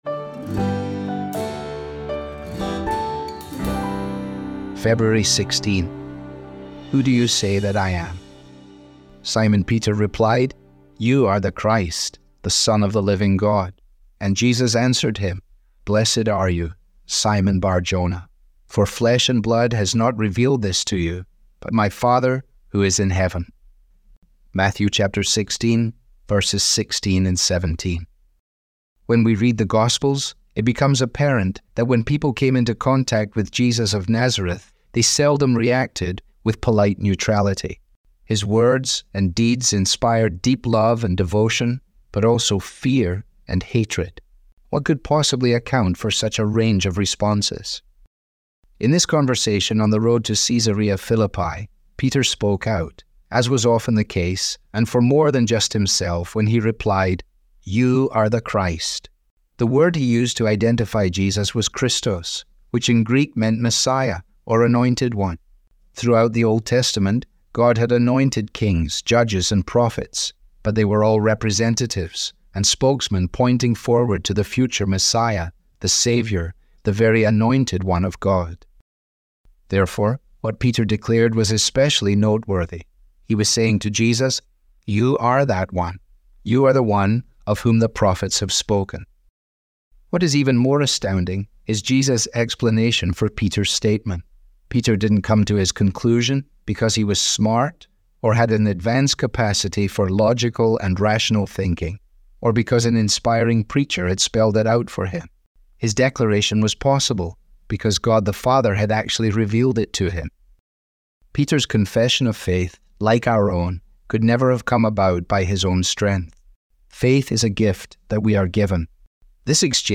Audio was digitally created by Truth For Life with permission.